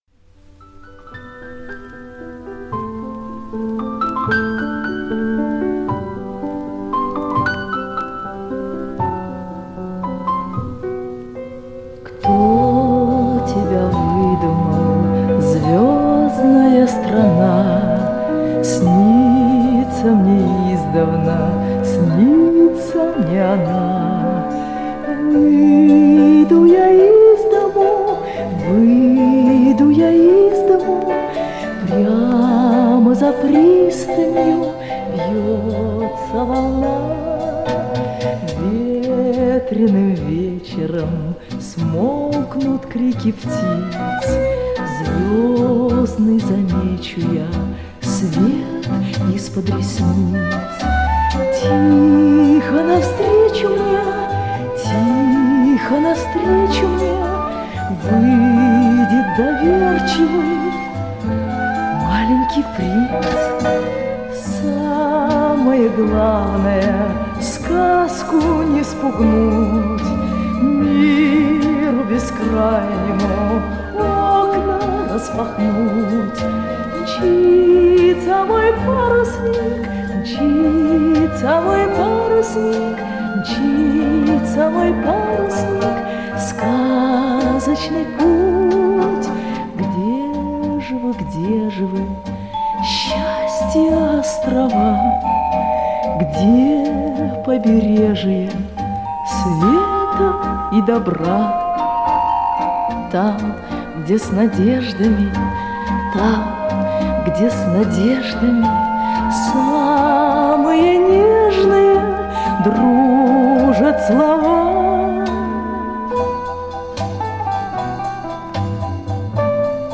песня из советского мультфильма
Нежная, сказочная песня